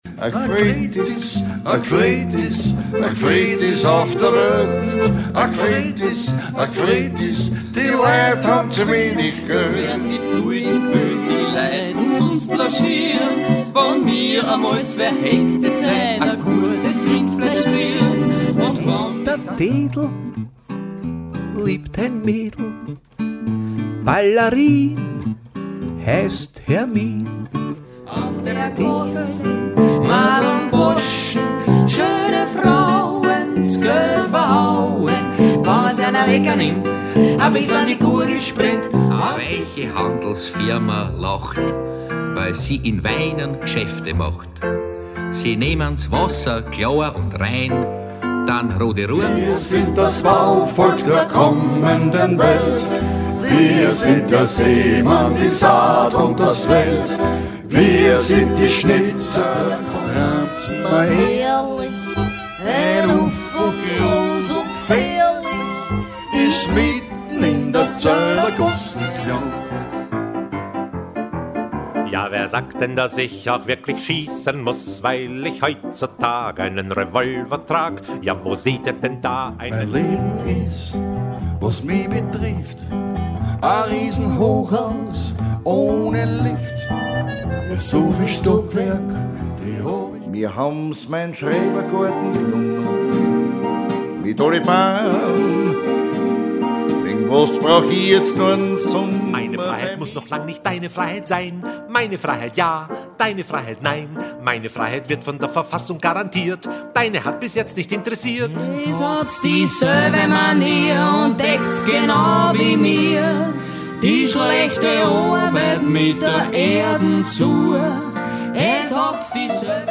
Hier hören Sie etwa 10 Sekunden aus jedem Musikstück